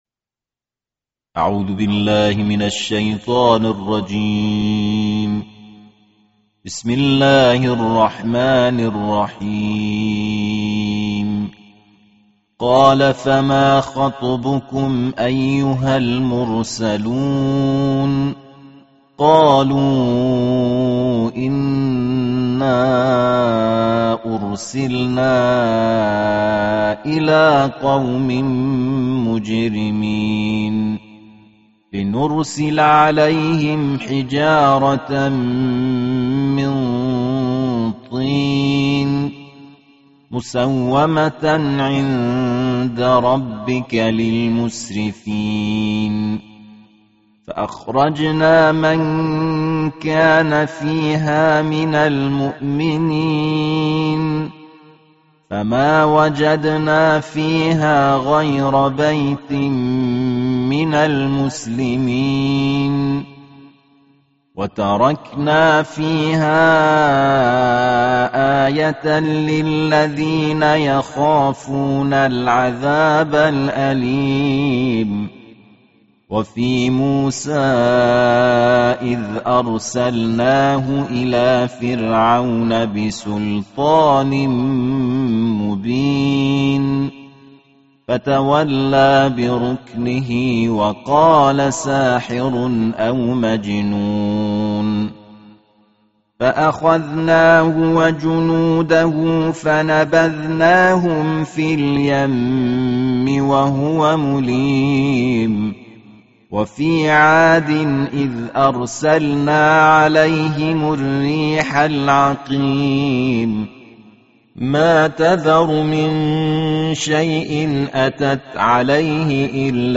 সুললিত কণ্ঠে ২৭তম পারার তিলাওয়াত